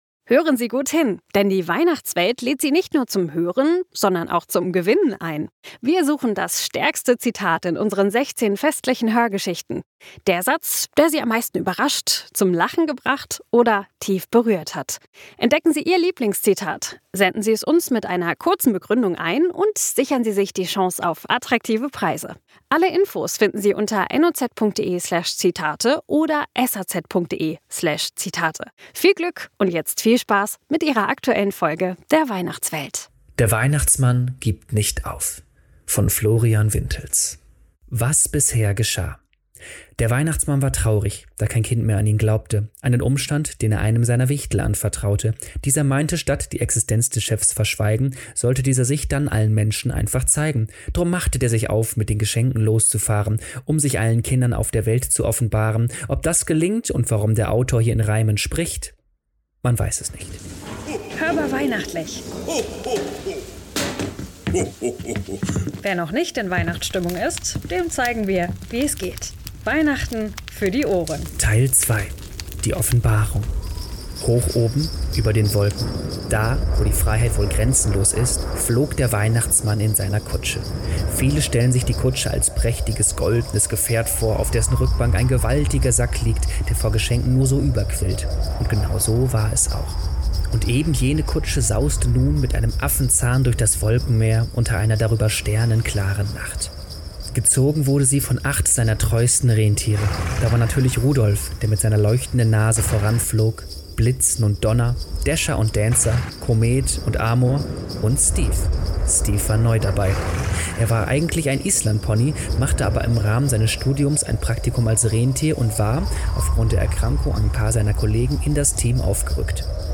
Text/Erzähler